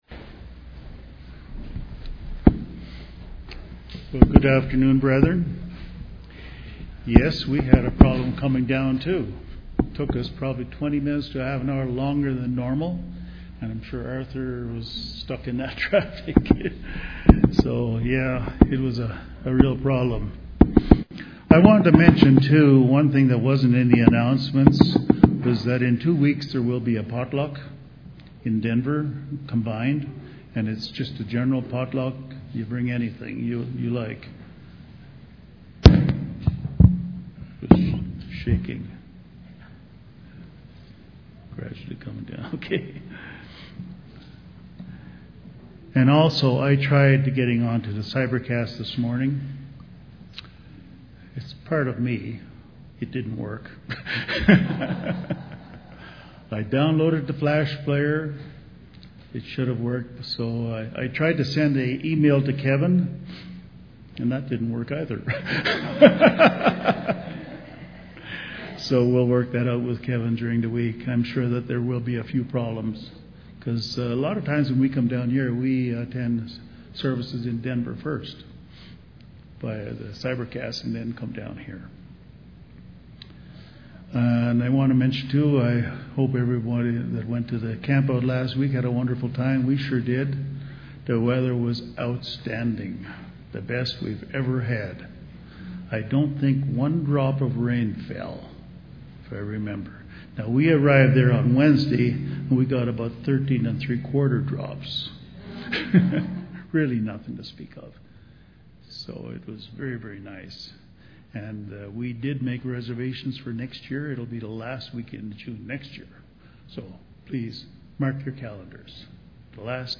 In this sermon I will define blessings, review basic Jewish traditions and expound on a blue-print for blessings in Num 6:24-27.
Given in Colorado Springs, CO